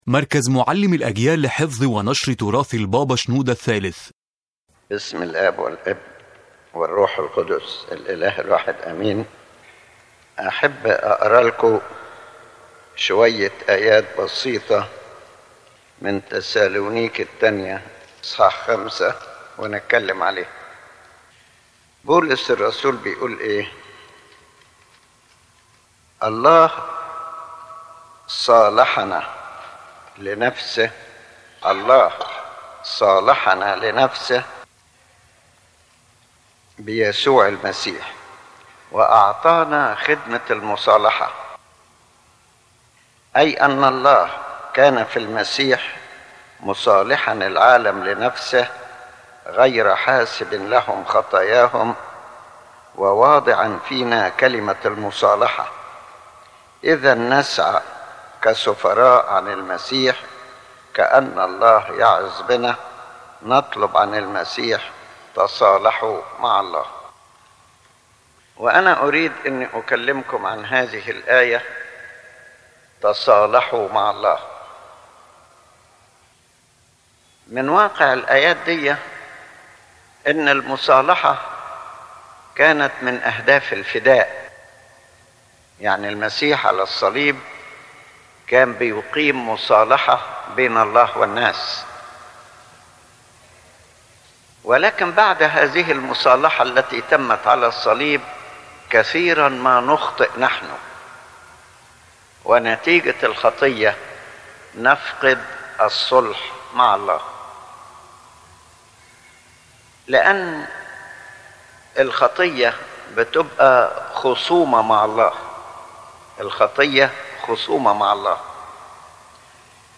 The lecture revolves around the call for a person to be reconciled with God, as reconciliation is one of the core goals of redemption accomplished on the Cross, through which God reconciled the world to Himself in Jesus Christ and called people to live this reconciliation practically.